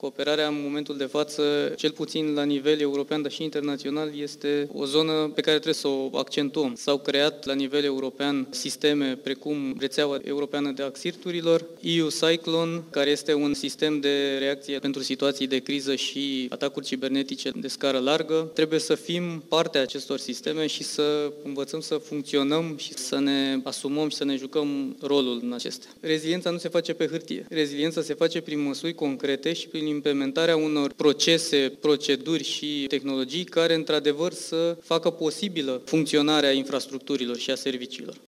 Adjunctul Directoratului Naţional pentru Securitate Cibernetică, Gabriel Dinu, spune că rezilienţa este cuvântul-cheie, iar prioritatea instituţiei, în prezent, este transpunerea în legislaţia naţională a directivelor europene, cu privire la securitatea cibernetică: